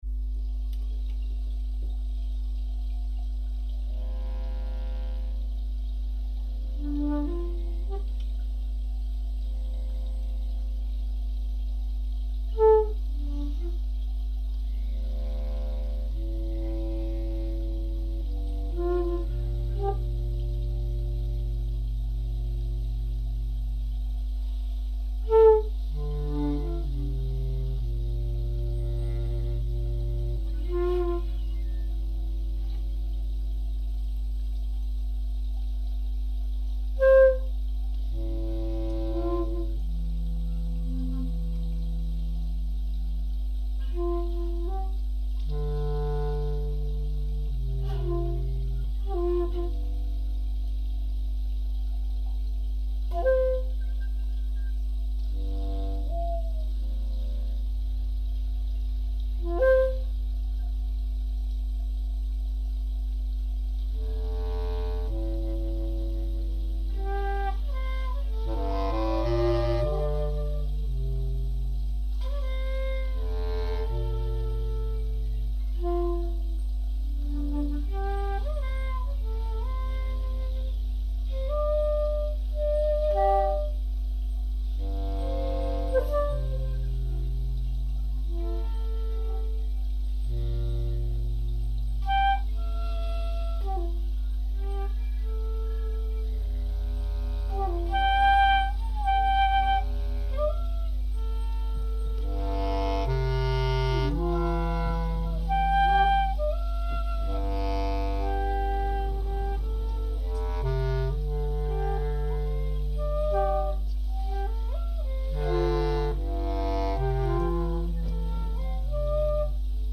Chorus of insects in Pantuase, Ghana reimagined